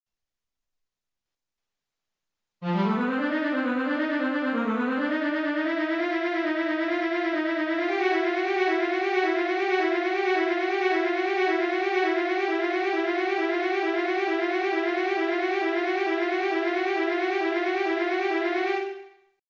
M１２６からは、同じような音が旋回しているのでただ順番に音を弾こうとするとなかなか難しい。